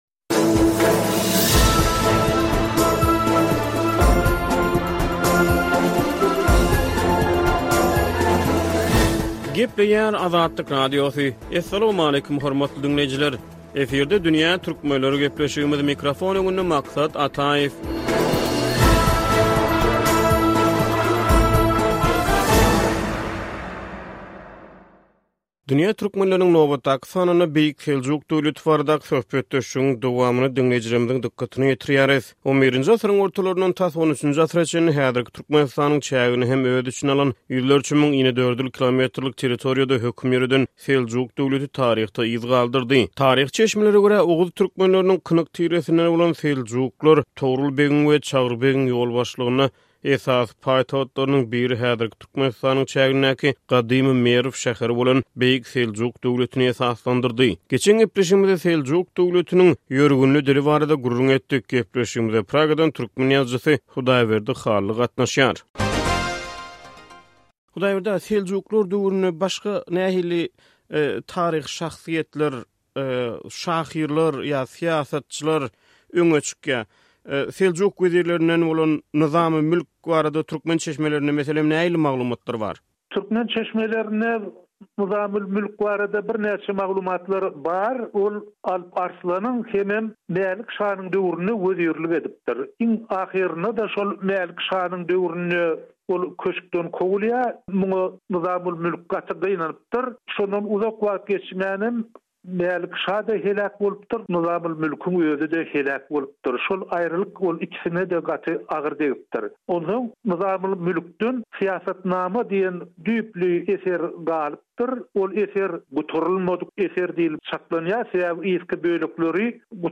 Dünýä Türkmenleriniň nobatdaky sanynda Beýik Seljuk döwleti baradaky söhbetdeşligiň dowamyny diňleýjilerimiziň dykgatyna ýetirýäris.